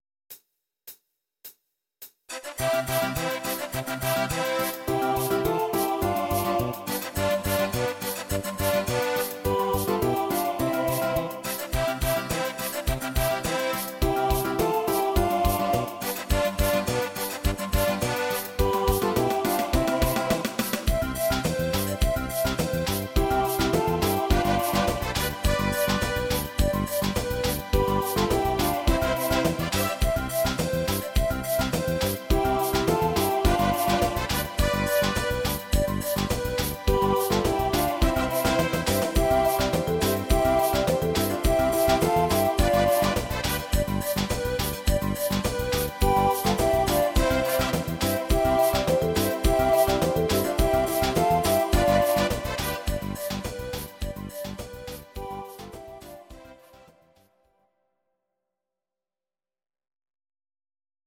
These are MP3 versions of our MIDI file catalogue.
Your-Mix: Disco (724)